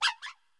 Cri de Pohm dans Pokémon Écarlate et Violet.